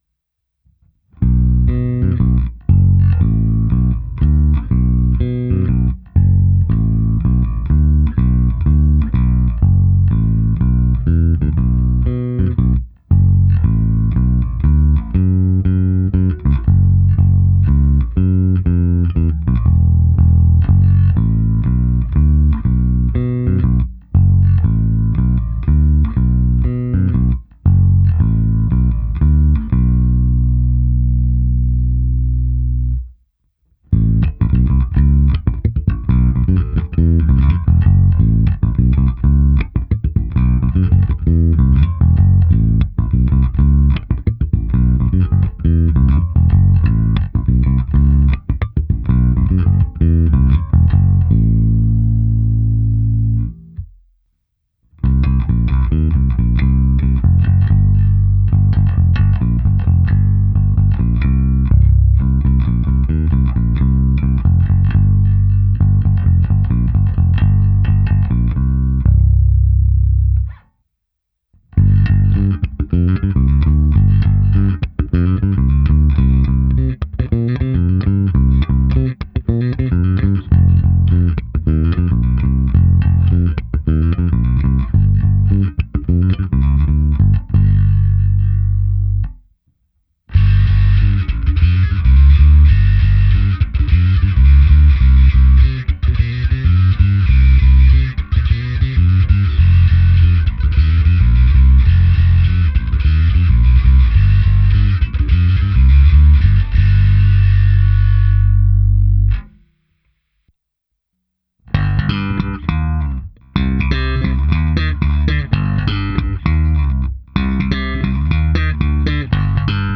Abych simuloval, jak hraje baskytara přes aparát, nechal jsem signál protéci preampem Darkglass Harmonic Booster, kompresorem TC Electronic SpectraComp a preampem se simulací aparátu a se zkreslením Darkglass Microtubes X Ultra. Hráno na oba snímače, v nahrávce jsem použil i zkreslení a slapovou techniku.
Ukázka se simulací aparátu